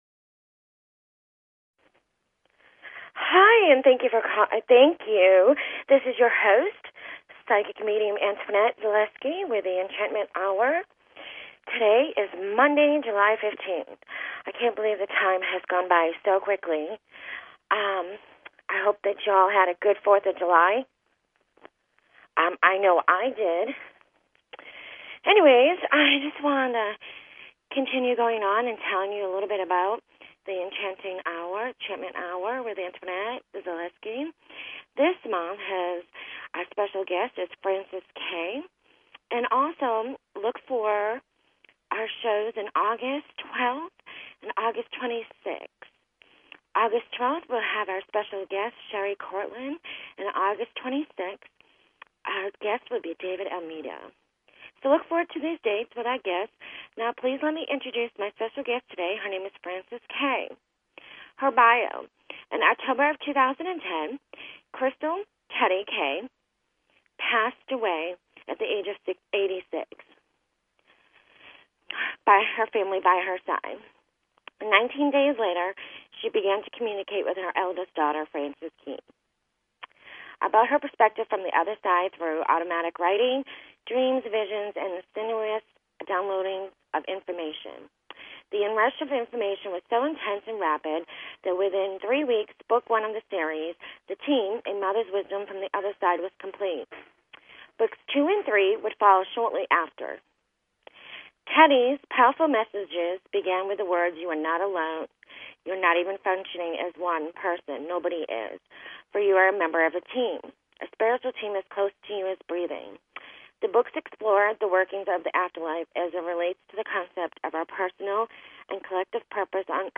Talk Show Episode, Audio Podcast, Enlightenment_Hour and Courtesy of BBS Radio on , show guests , about , categorized as